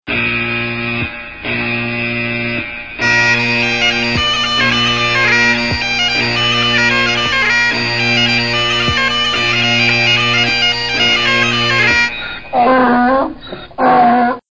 The Great Irish Donkey Alarm
Tags: donkey work evil users